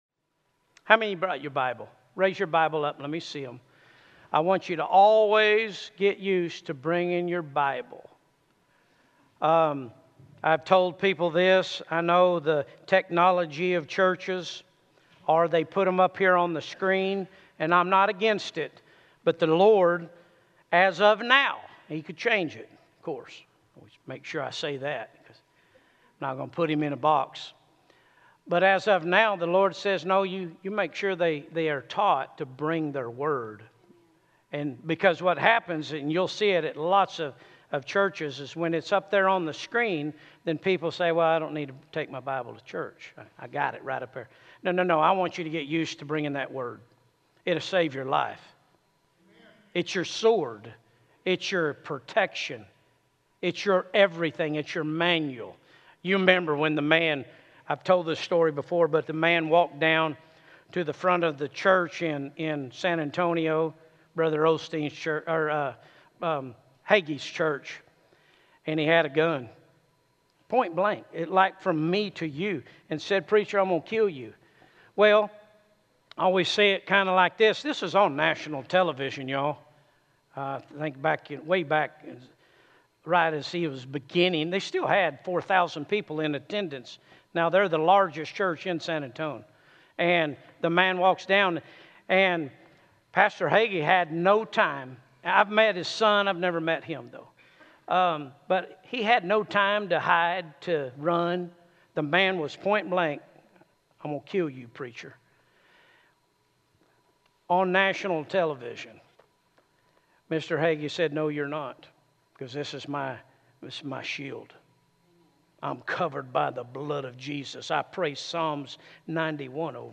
Sermons Archive ⋆ Page 3 of 45 ⋆ Williamson County Cowboy Church - Liberty Hill, TX